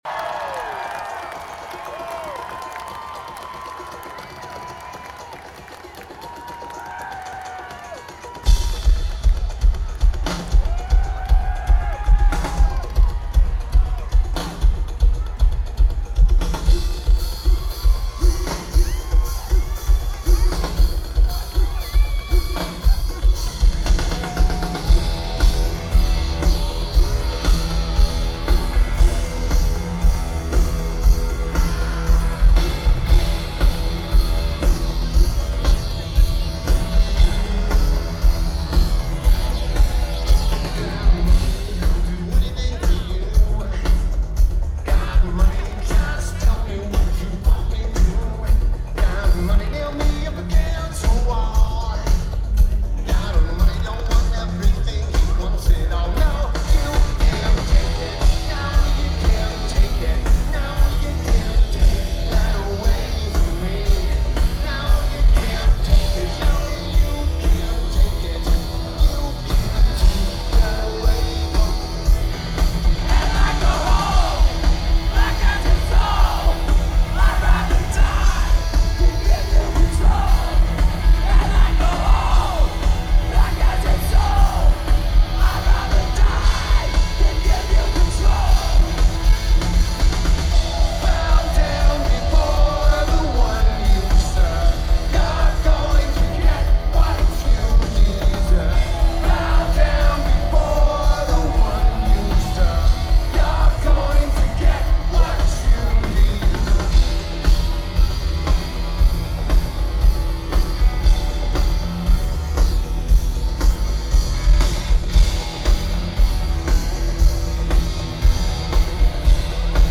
The Palladium
Los Angeles, CA United States
Lineage: Audio - AUD (CSB + Digital Recorder)
This was recorded at 16/96 and not sure on the recorder.